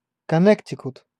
Ääntäminen
Ääntäminen US : IPA : [kə.ˈnɛɾ.ɪ.kət] Tuntematon aksentti: IPA : /kəˈnɛ.tə.kət/ Haettu sana löytyi näillä lähdekielillä: englanti Käännös Ääninäyte Erisnimet 1.